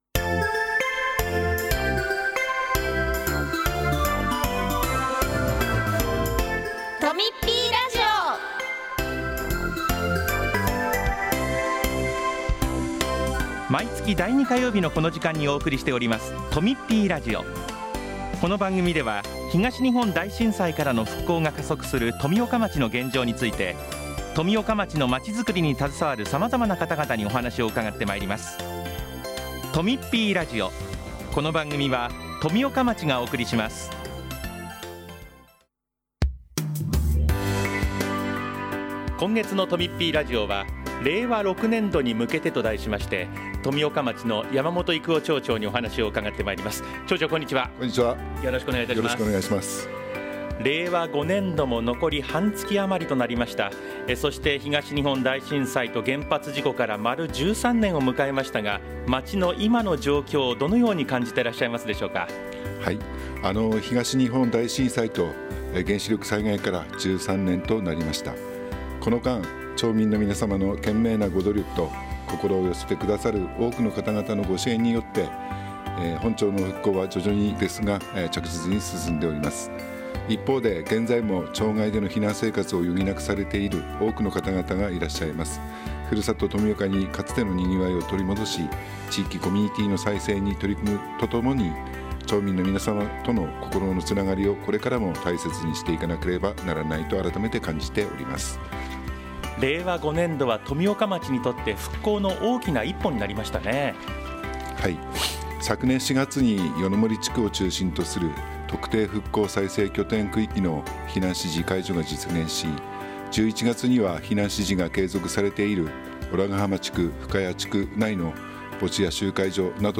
今回は、「令和6年度に向けて」というテーマで山本町長がお話ししています。